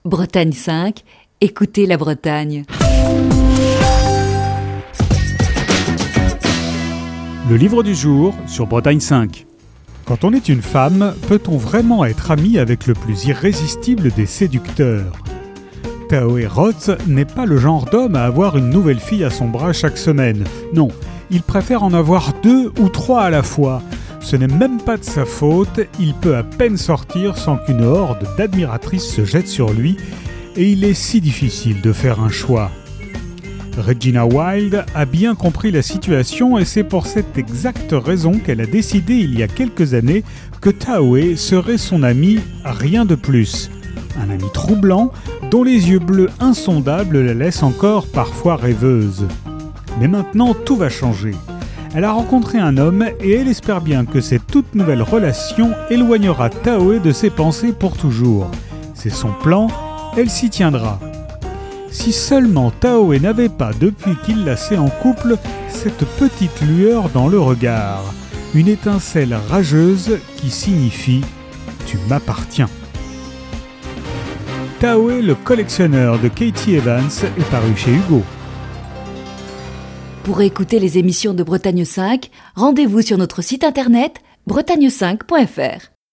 Fil d'Ariane Accueil Les podcasts Tahoe, le collectionneur - Katy Evans Tahoe, le collectionneur - Katy Evans Chronique du 28 décembre 2020.